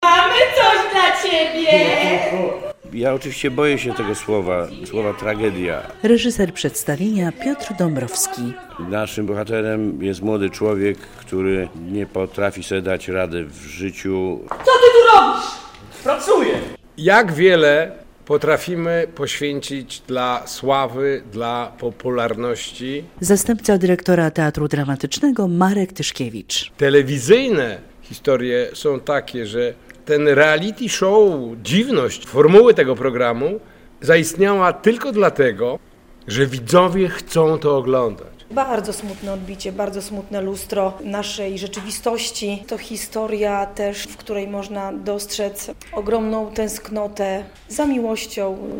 Radio Białystok | Wiadomości | Wiadomości - Telewizyjne reality show w teatrze, czyli Trup w Dramatycznym